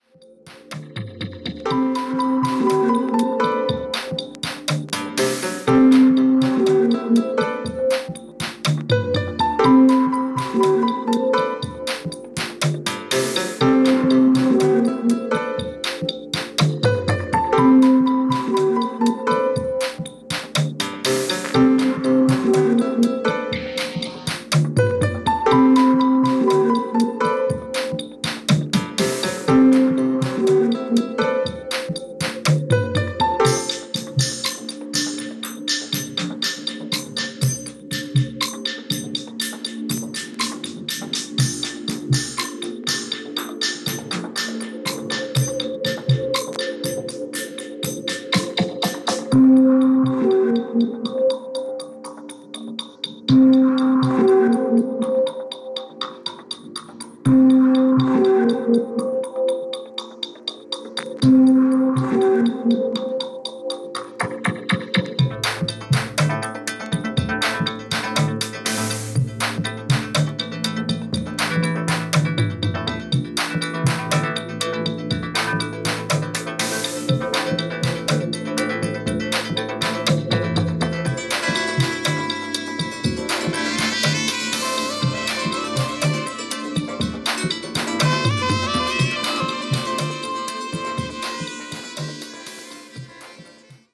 NY、イスラエルに住居を移し、2016年にサンフランシスコに戻って録音された傑作！！！